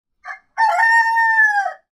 めばえ４月号 ニワトリのなきごえ